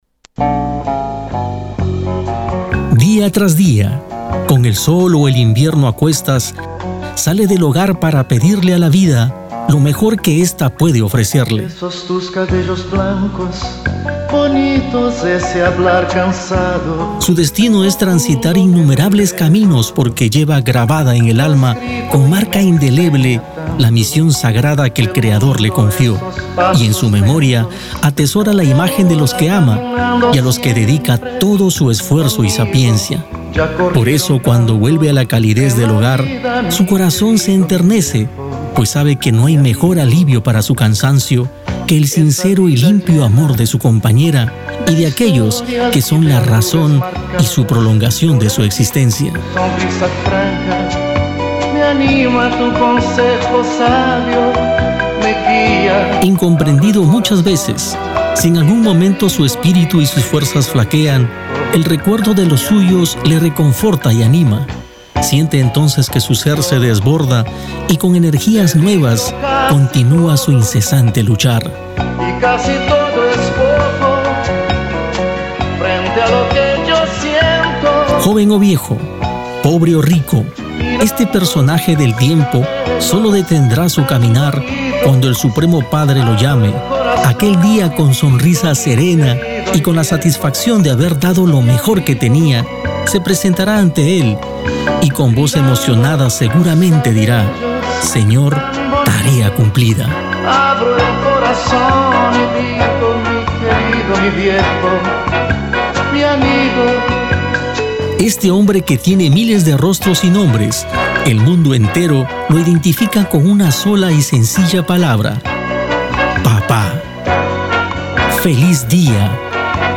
Aquí encontrara algunas muestras de  diferentes producciones realizadas en los studios de AUDIO TOOLS PERU.
Anuncios y comerciales para radio, institucionales, spot para eventos bailables, spot para conciertos, spot para TV , spot genericos.